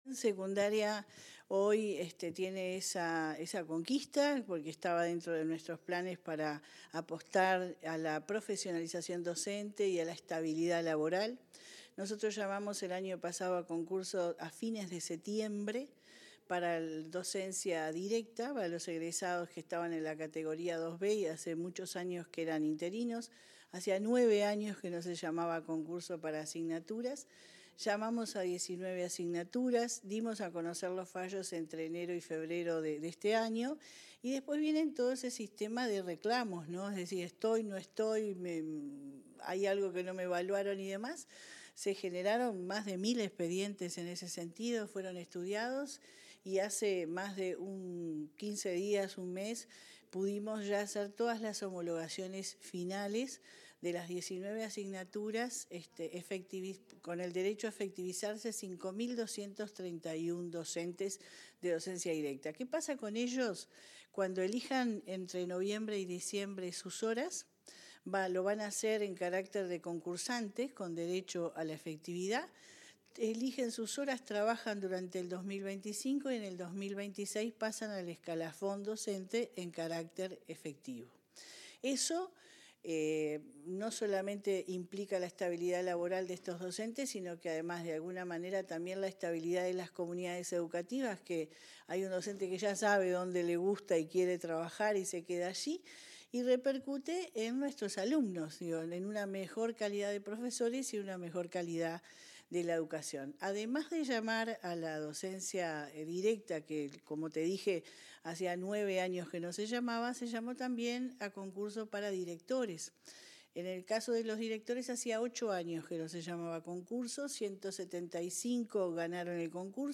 Entrevista a la directora general de Secundaria, Jenifer Cherro